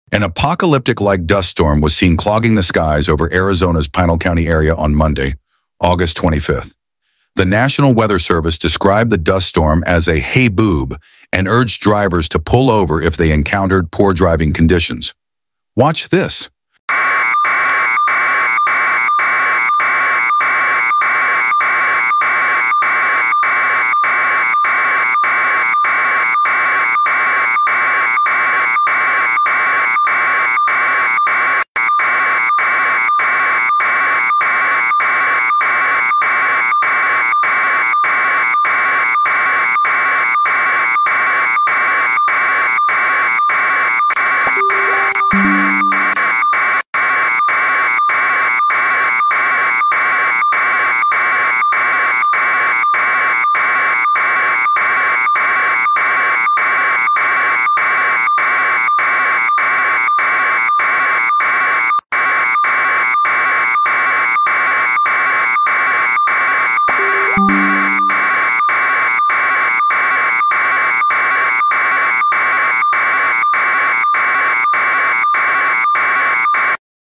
APOCALYPTIC-LIKE DUST STORM SWEEPS THROUGH